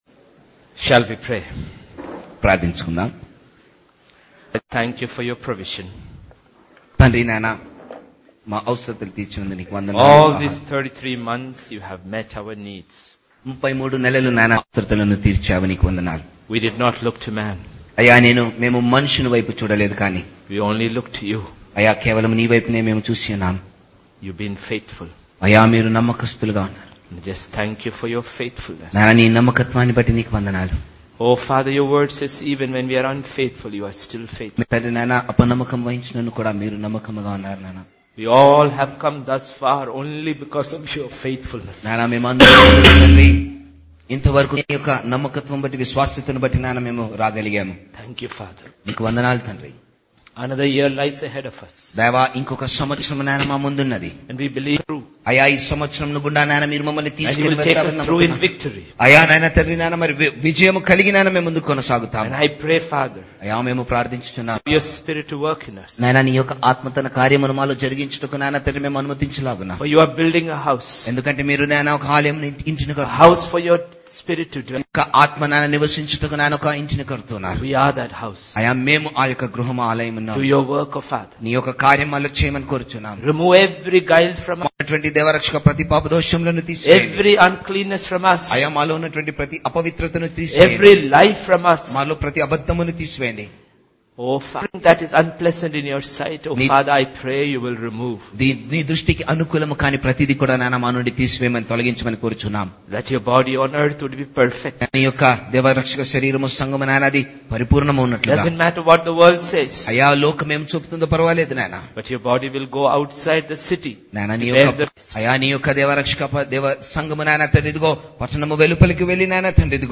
Pastors Conference 11th Jan 2014 Session 2